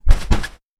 Punching Bag Powerful C.wav